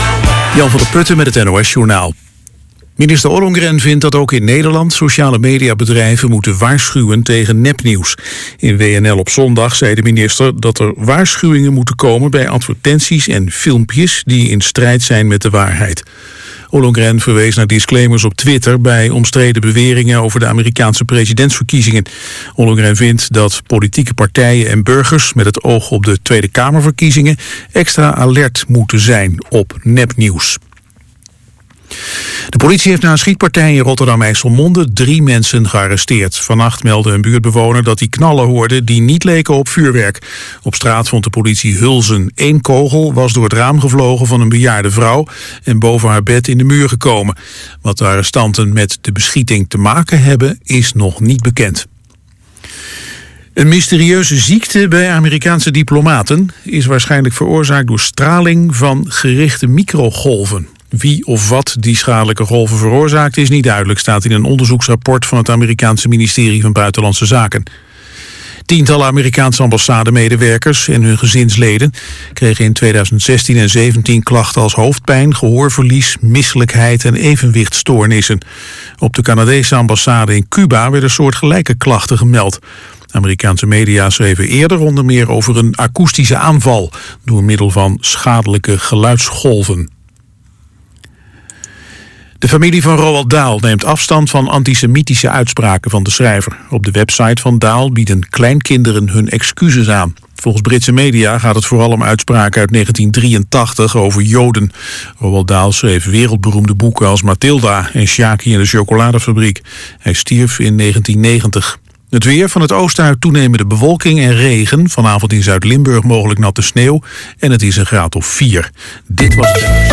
Jean Ferrat en Léo Ferré waren grote voorvechters van het Franse lied en worden beschouwd als de grote namen van dit genré 'Le Chanson',
“Uit de Tijd van Toen” wordt elke zondagmiddag uitgezonden via BR6, van 13:00 tot 14:00 uur en ’s avonds van 22:00 tot 23:00 uur (herhaling).